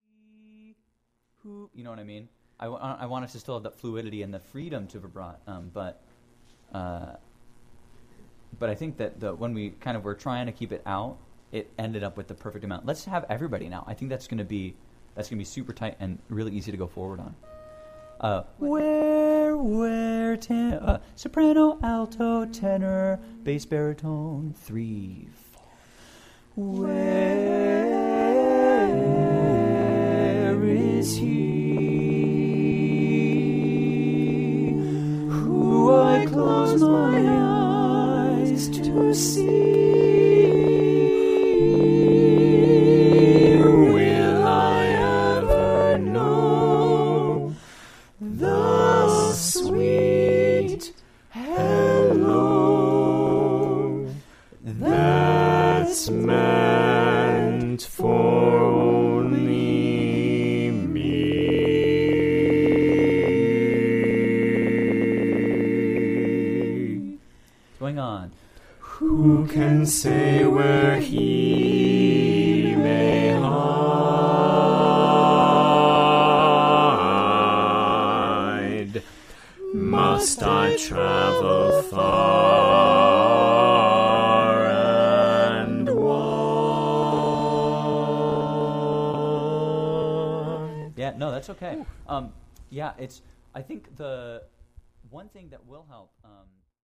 Jamulus for bluegrass
Click the image below to hear the session captured in the screenshot, with over 20 people singing together:
The screenshot and recording above were taken from a Jamulus session.
VocalJamSample.mp3